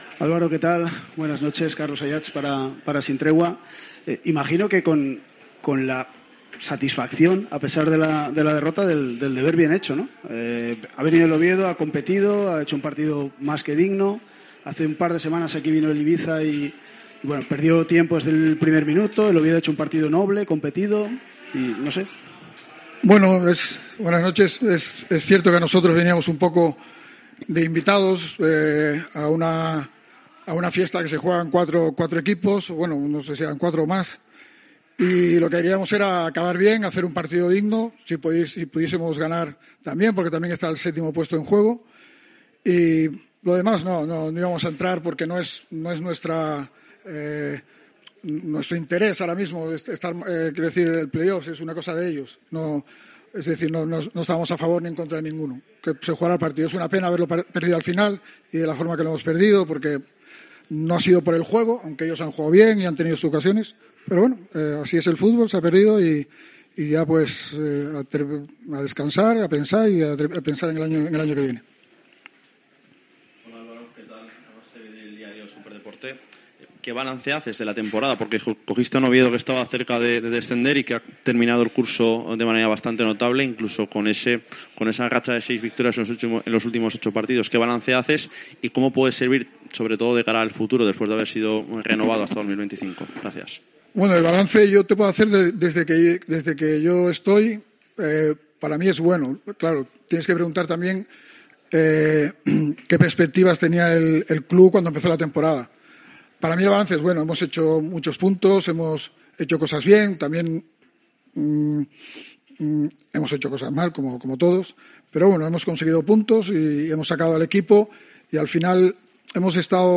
Rueda de prensa de Álvaro Cervera (post Levante)